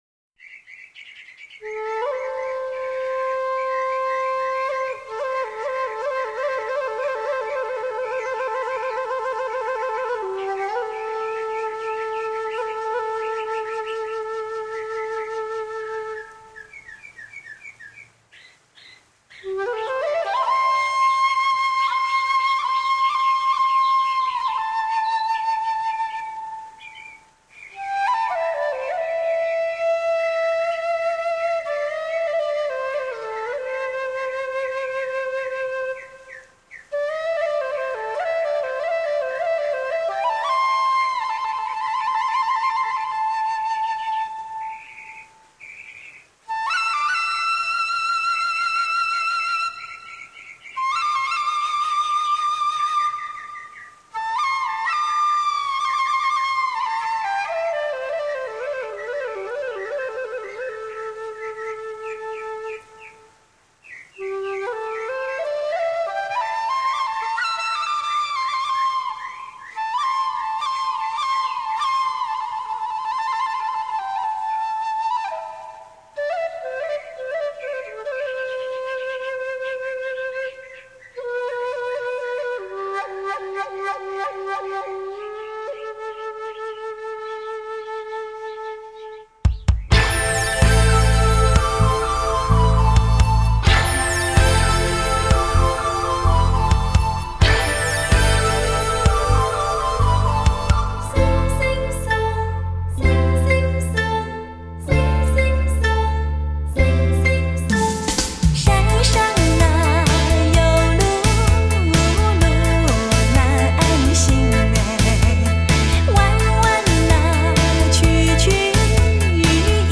超级柔情 经典贴心